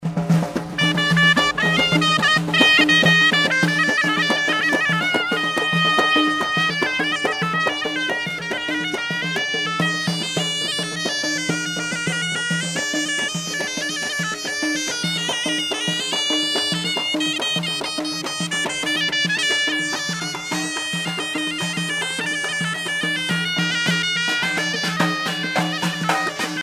Musique de charmeur de serpents
Pièce musicale éditée